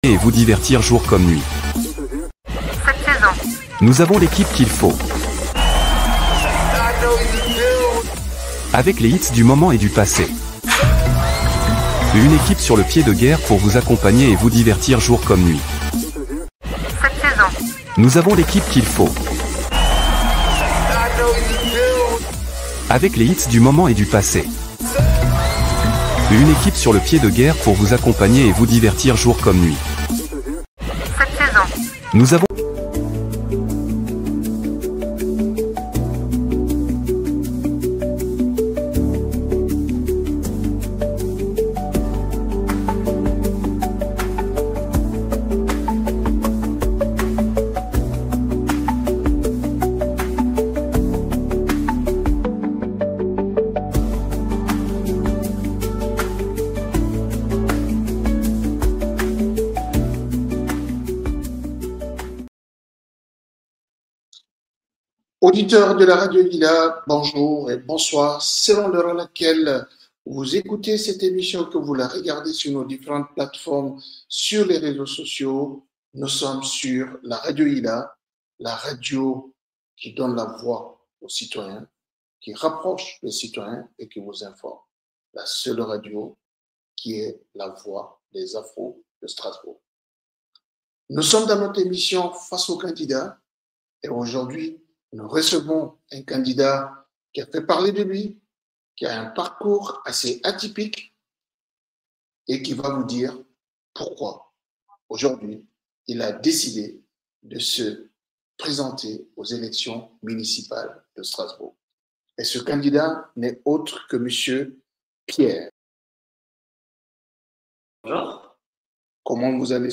Dans le cadre de notre série consacrée aux élections municipales 2026, la deuxième émission spéciale s’est tenue sur les ondes de Radio Ylla – La Voix Afro de Strasbourg.
Nous avons reçu en direct Pierre Jakubowicz Candidat de l’Union des centristes et des progressistes aux élections municipales de Strasbourg.